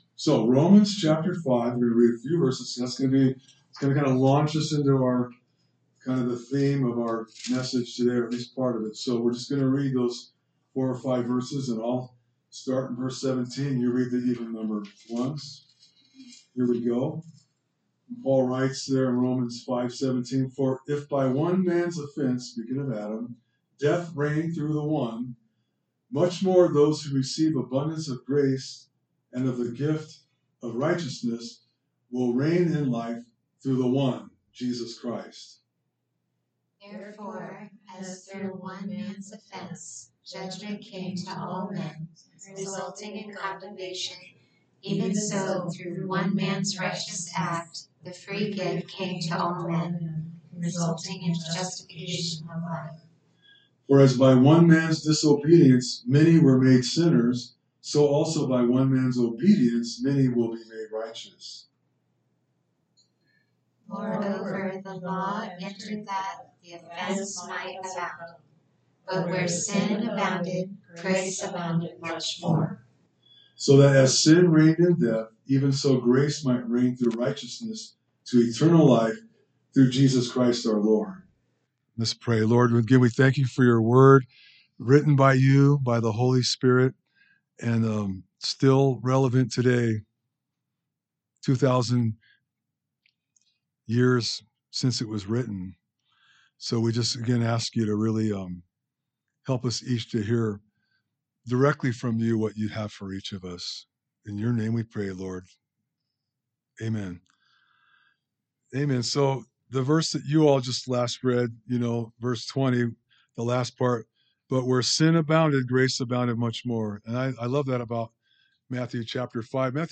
A message from the series "Our Identity in Christ."